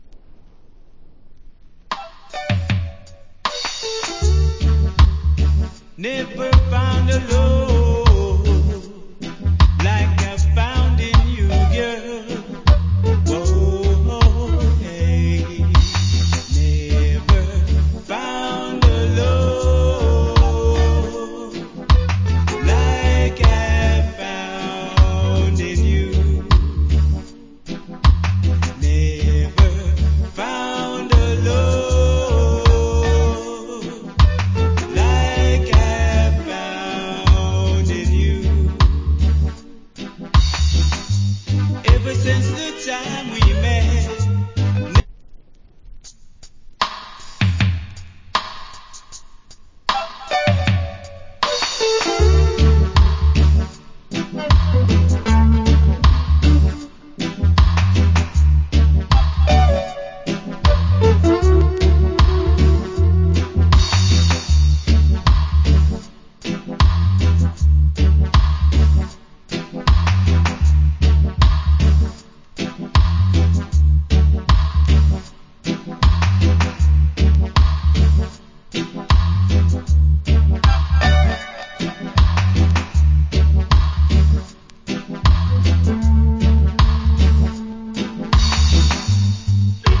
Cool UK Lovers Rock Vocal.